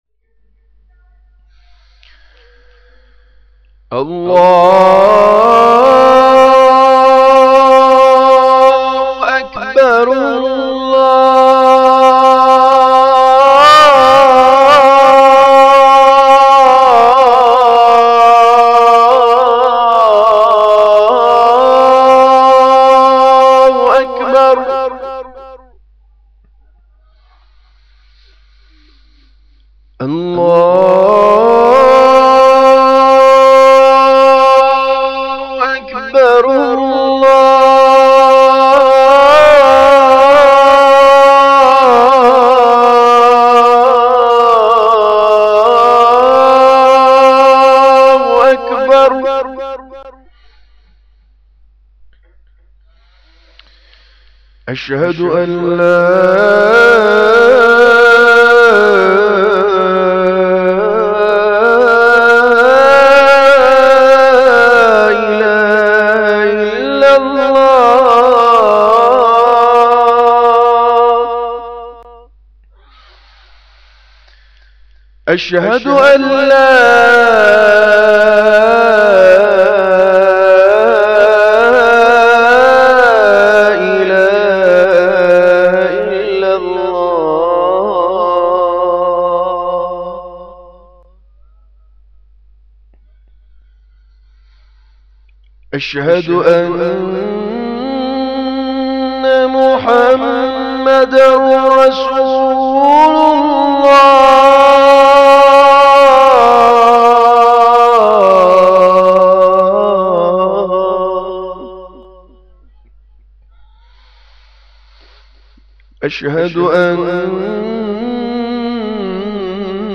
azan.mp3